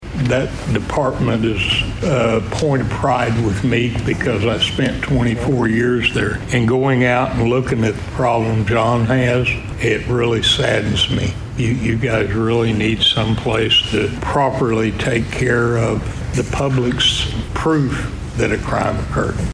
District Three Commissioner Charlie Cartwright used to work in the sheriff's office